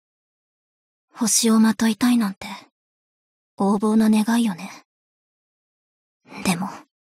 贡献 ） 协议：Copyright，其他分类： 分类:爱慕织姬语音 您不可以覆盖此文件。